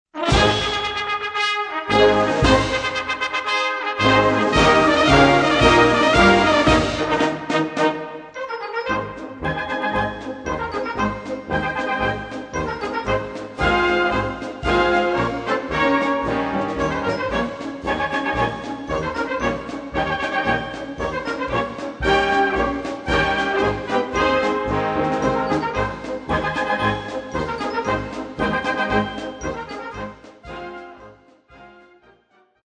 Benefizkonzert mit dem Luftwaffenmusikkorps  und der Gruppe "Akkord" der Lessya-Universität
Ein Höhepunkt unseres Vereinslebens war das Benefizkonzert mit dem Luftwaffenmusikkorps Münster am Dienstag 04. November 2008 um 19:30 Uhr im Stadttheater Herford werden.
Zusammen mit dem Männerquartett „Akkord" von der Lessya-Ukrainka-Universität aus Lutsk haben wir die Zielrichtung unserer Arbeit hier sinnfällig werden lassen, zwischen der Ukraine und unserem Land Brücken zu schlagen - durch konzertante und flotte Musik.